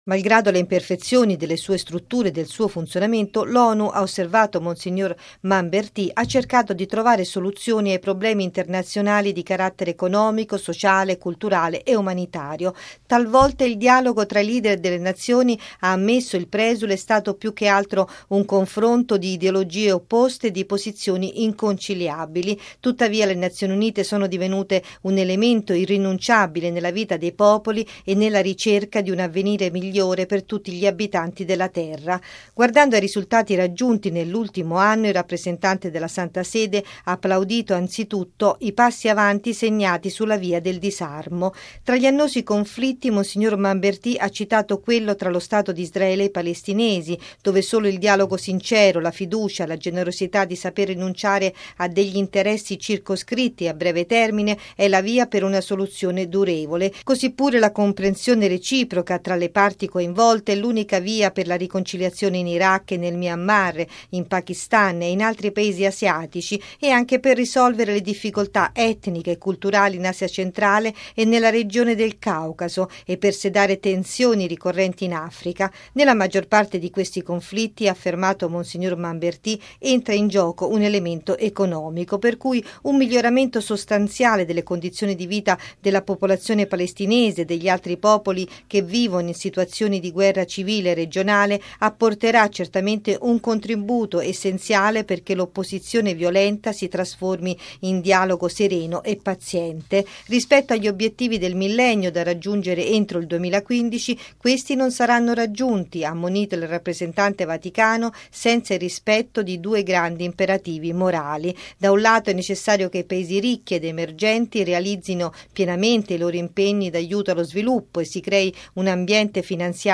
Radio Vaticana - Radiogiornale